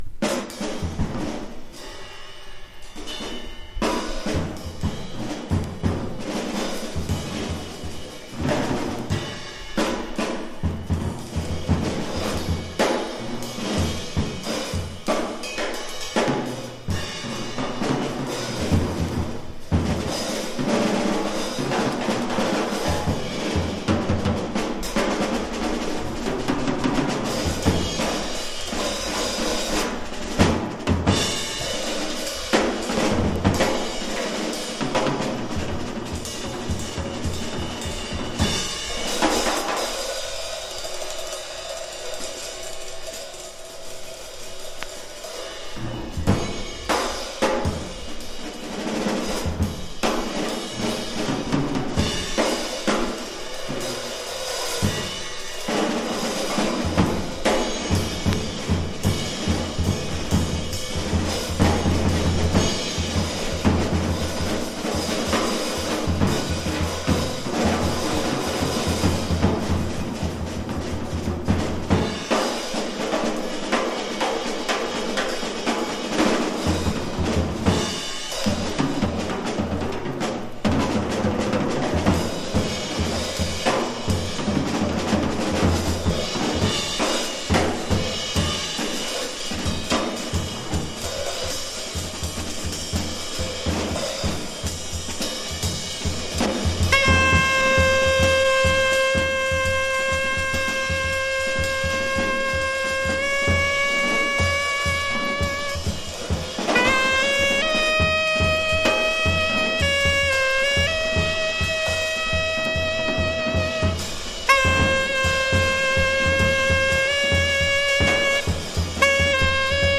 フリー・ジャズ・ファンク!!